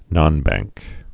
(nŏnbăngk)